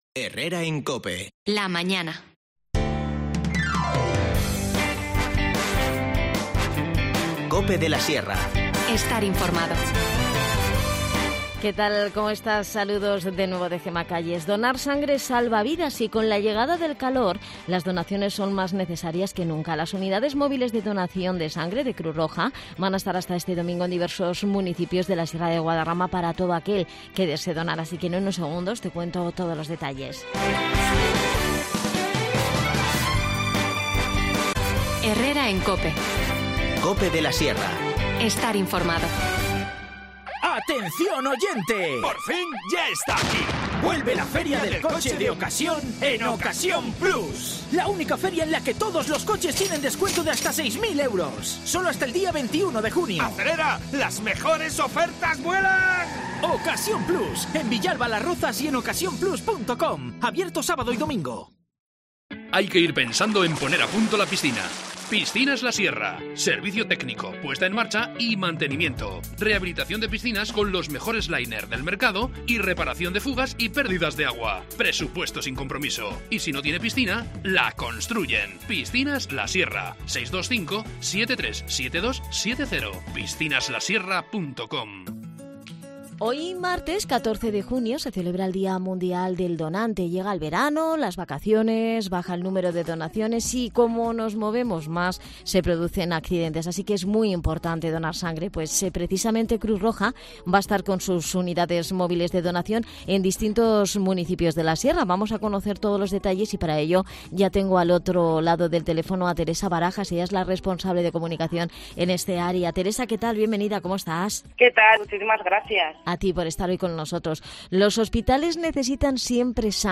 Todo gracias al equipo de profesionales de COPE de la Sierra, que te acercarán toda la actualidad y los temas más candentes.